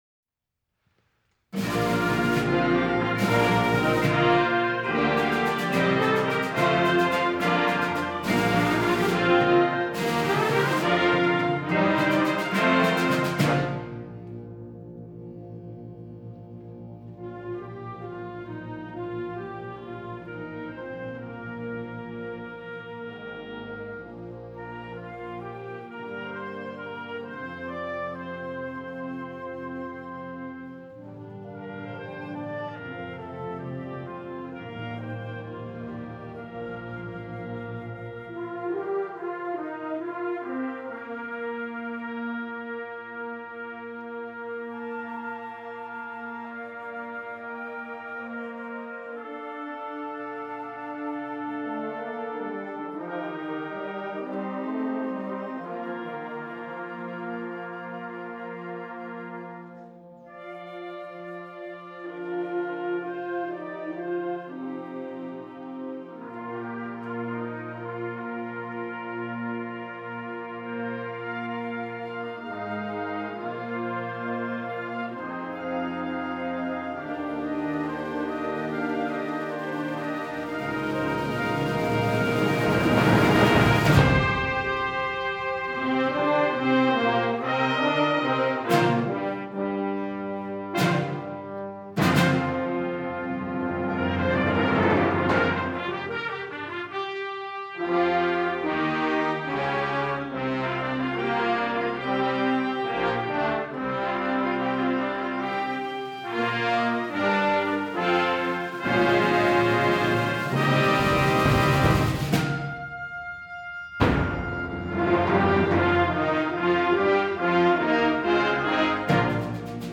Genre: Band
Mallet Percussion [3 Players] (Bells, Vibraphone, Chimes)
Timpani (4)